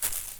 grass2.wav